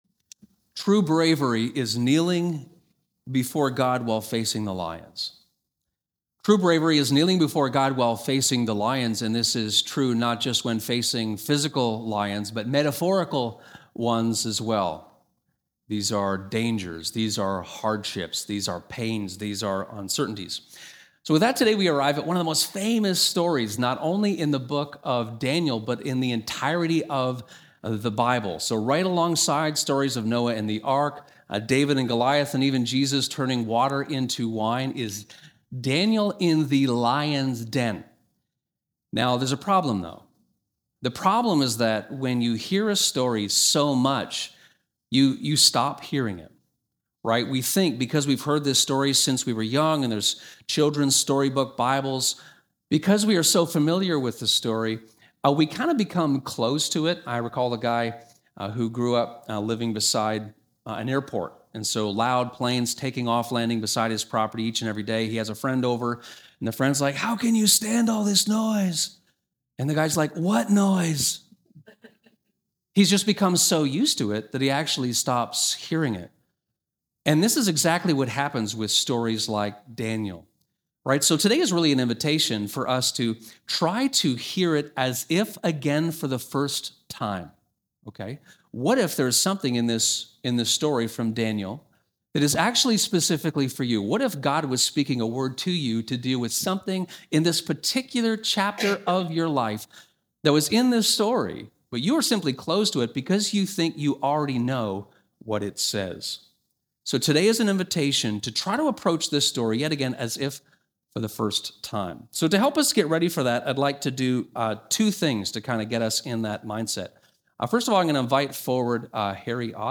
This sermon is an invitation to explore Daniel and the Lion’s Den as if for the first time. It unpacks Daniel 6, the brutality of the ancient world, and uncovers what actually happened and why.